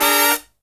FALL HIT12-R.wav